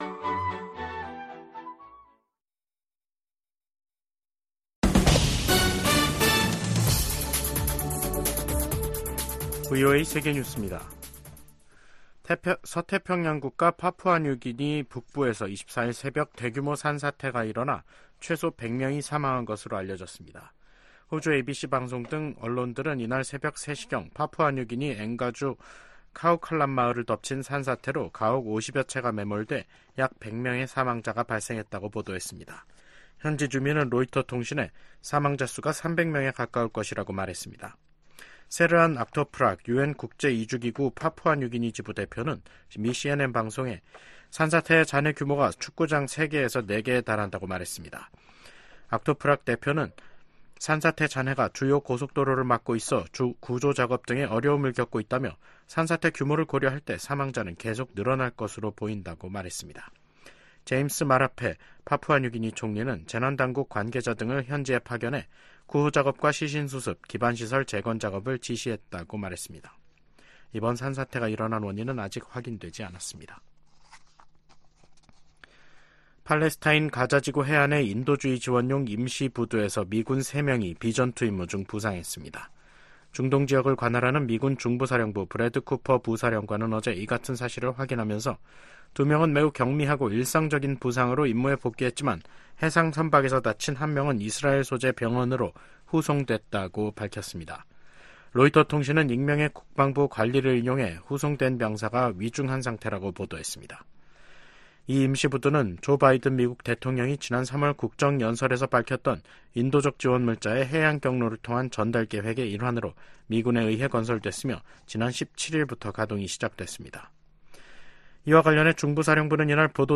VOA 한국어 간판 뉴스 프로그램 '뉴스 투데이', 2024년 5월 24일 3부 방송입니다. 미 국무부는 미국과 그 동맹들이 역내 긴장을 고조시킨다는 러시아의 주장을 일축하고, 긴장 고조의 원인은 북한에 있다고 반박했습니다. 2025회계연도 미국 국방수권법안이 하원 군사위원회를 통과했습니다.